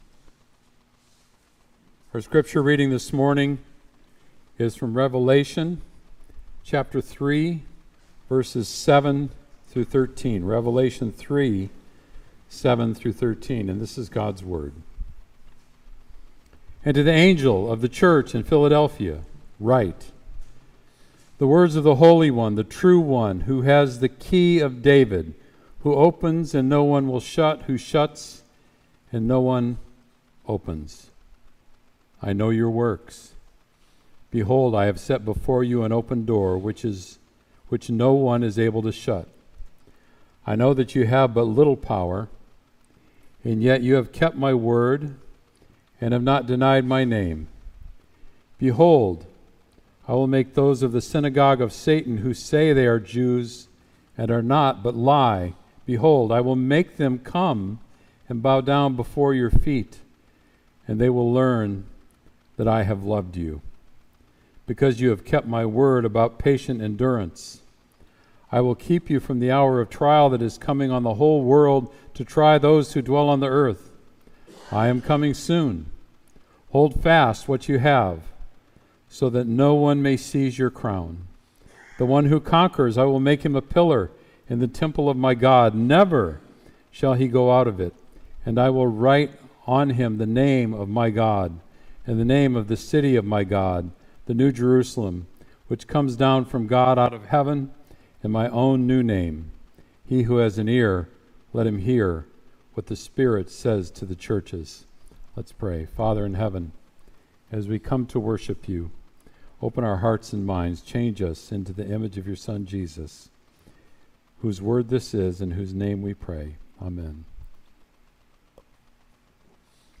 Sermon “An Open Door”